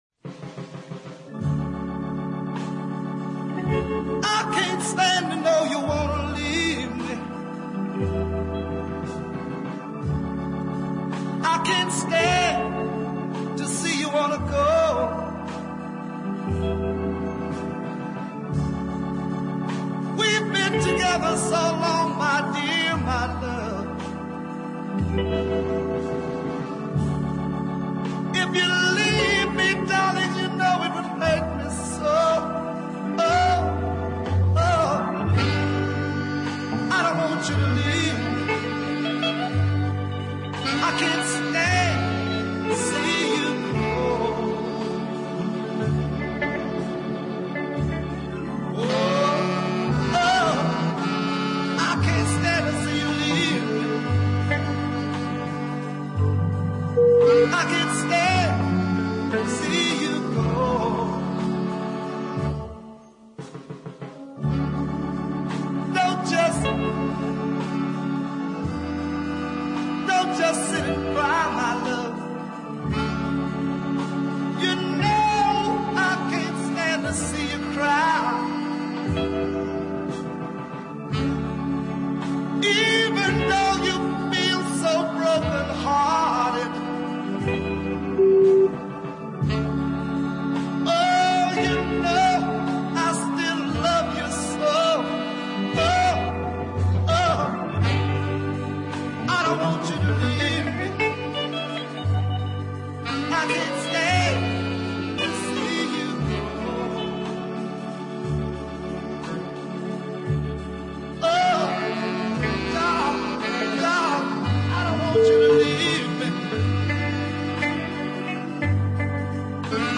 is really outstanding deep soul